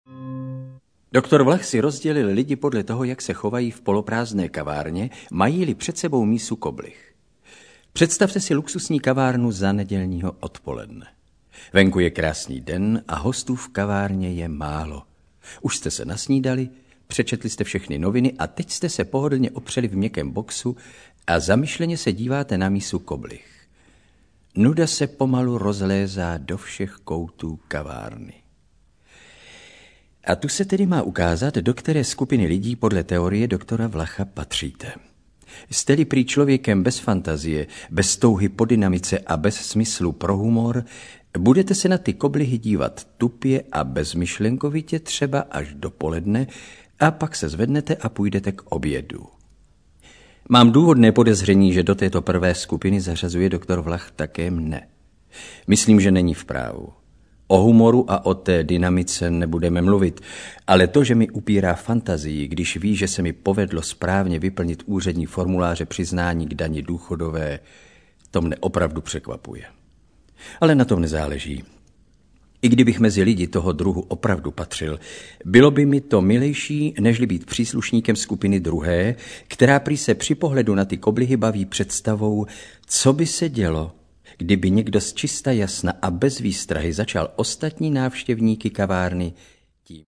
Vypočujte si ukážku audioknihy
Ve filmu zazářil v roli svérázného sluhy Saturnina Oldřich Vízner, který se také ujal hlavní role v audioknize.